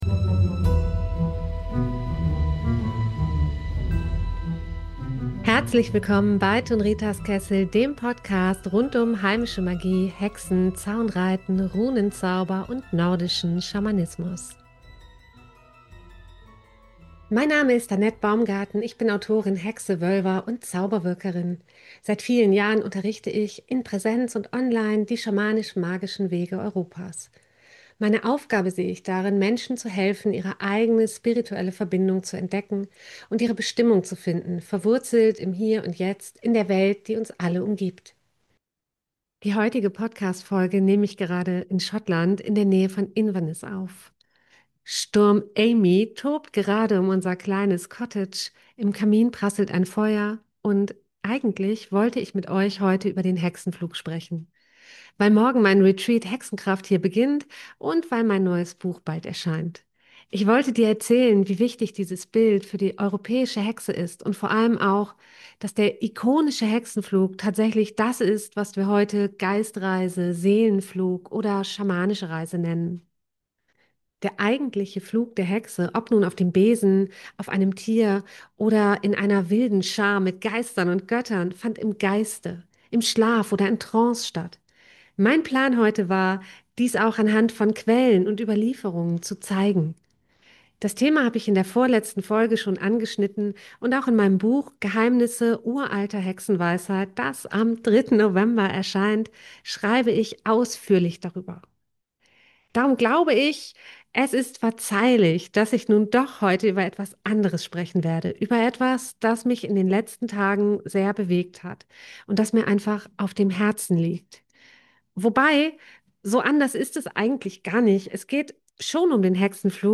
In dieser Folge, aufgenommen während eines stürmischen Abends in Schottland, spreche ich darüber, warum der Hexenflug weit mehr ist als ein alter Mythos. Er ist der Schlüssel zu unserer spirituellen Verbundenheit.